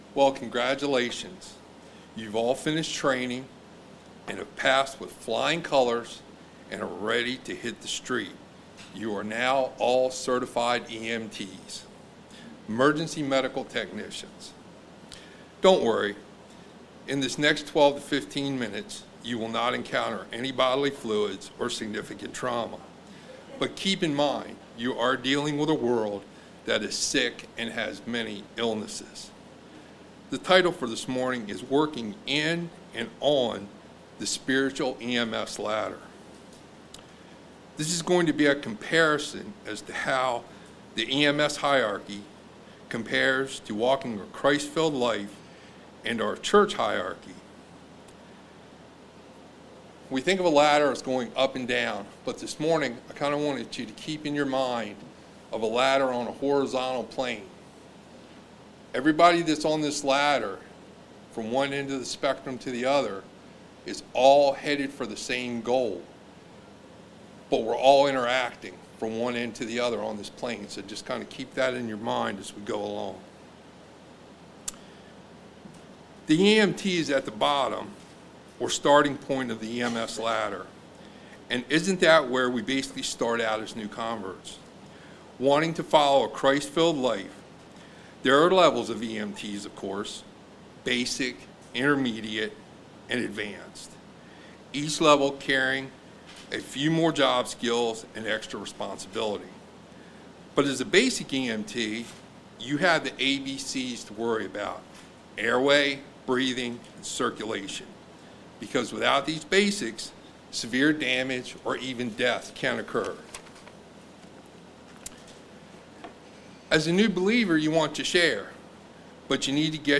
We are dealing with a world that is sick and has many illnesses. This sermonette compares the EMS hierarchy to a Christ filled life and our Church hierarchy.